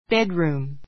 bedroom A1 bédruːm ベ ド ルー ム 名詞 寝室 しんしつ 文化 bedroom 英米では2階に寝室があることが多いから come down to breakfast は「（2階の寝室から）朝食に下りて来る」の意味.